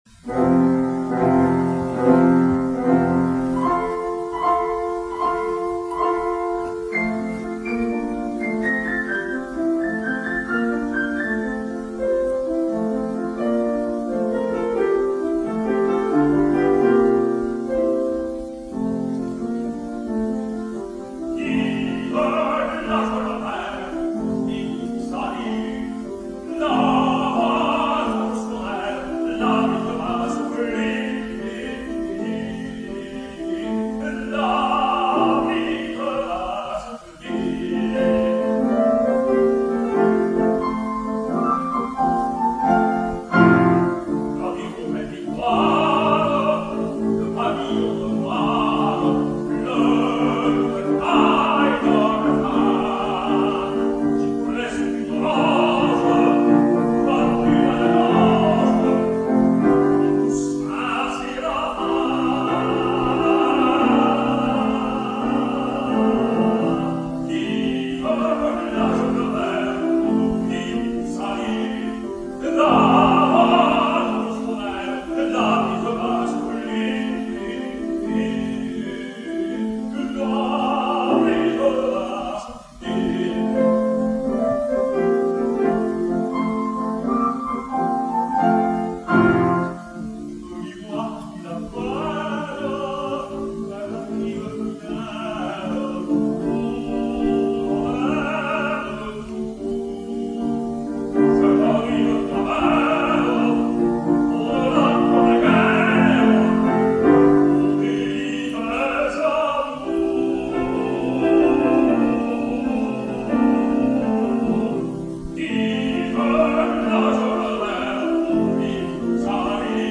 Liederabend
Nicolai Gedda, Tenor
Klavier